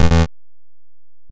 fbrawl_error.ogg